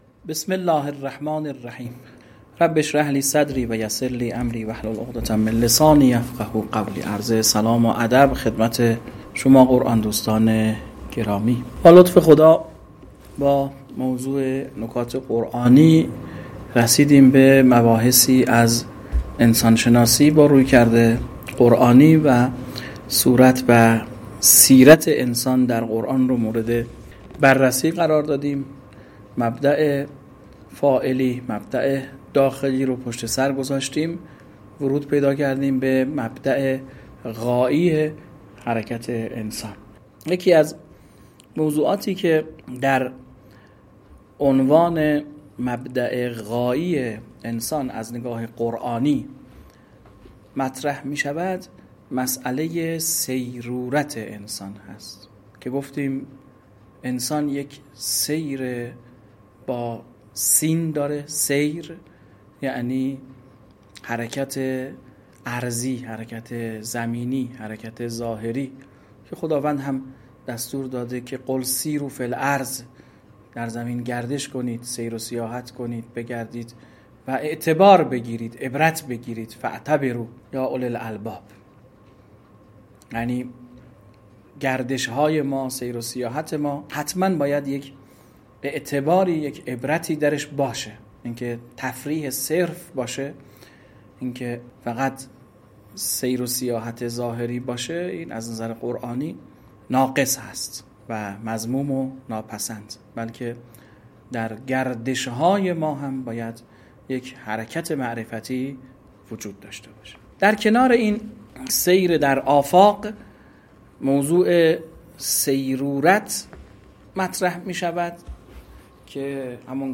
مراتب صیرورت انسان در قرآن مباحث مطرح شده در این درس: مراتب صیرورت انسان به مراتب اسمای لفظی خدای سبحان برمیگردد.